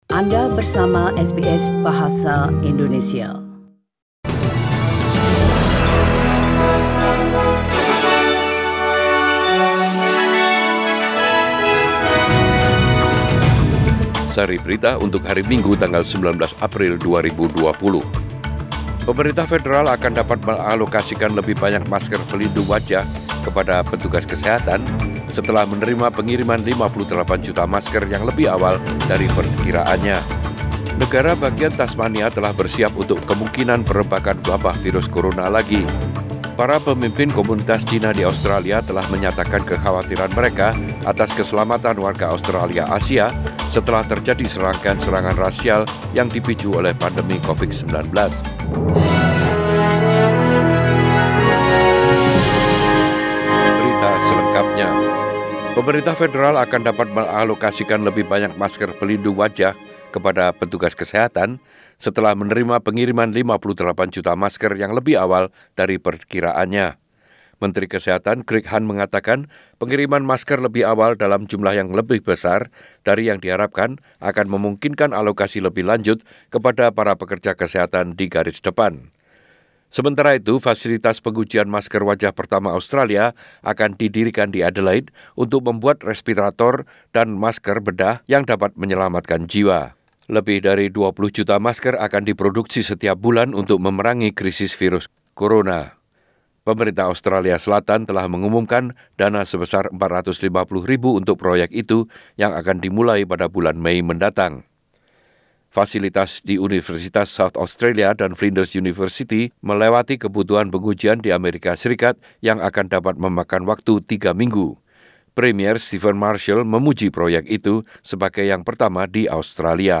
News SBS Radio News delivered in Indonesian edition 19 April 2020.